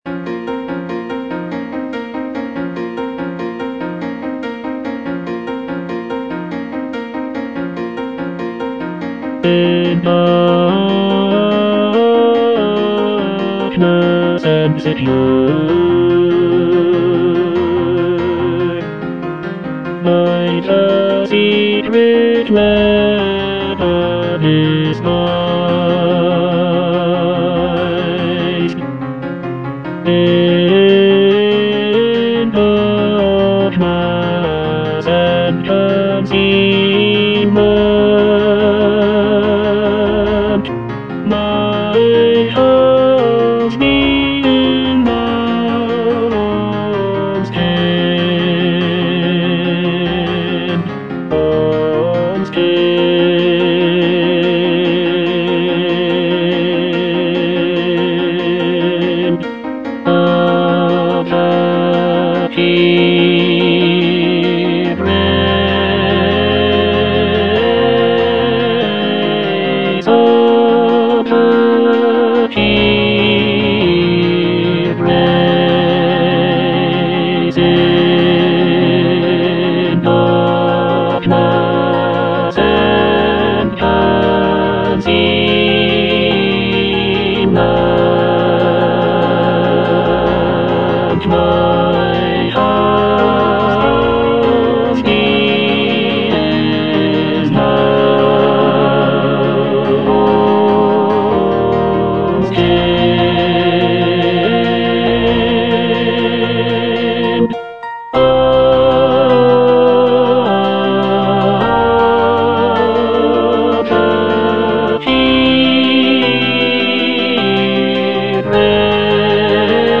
(tenor II) (Emphasised voice and other voices) Ads stop
choral work